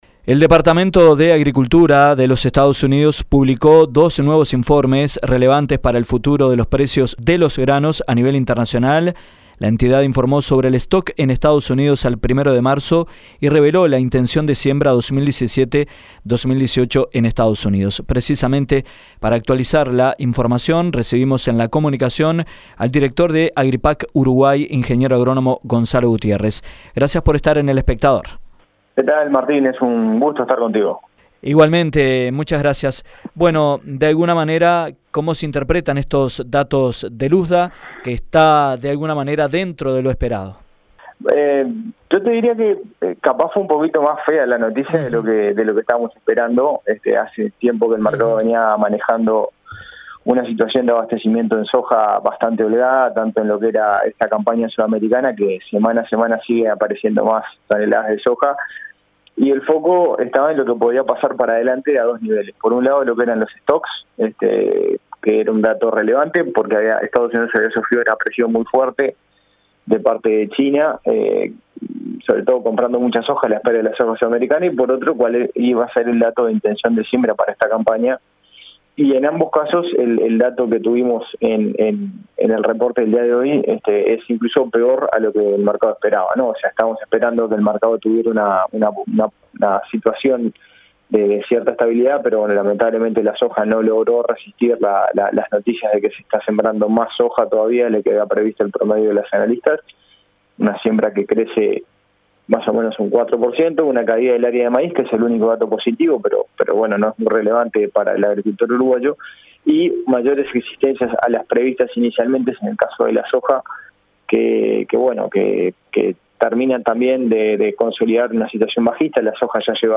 El organismo informó sobre los stocks en EE.UU al 1° de Marzo, y reveló la intención de siembra 2017/2018 en EE.UU, que podría ubicarse por encima de las 35 millones de hás. En entrevista